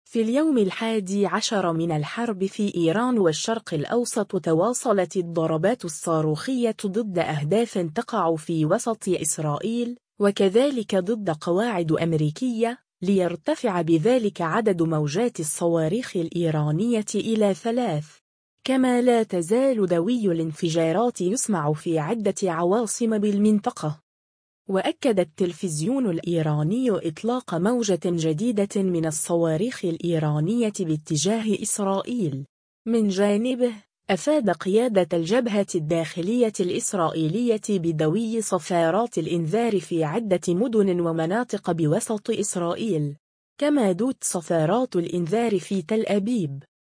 صفارات الإنذار تدوي في تل أبيب